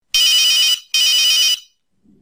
Free MP3 ringtones sound effects 3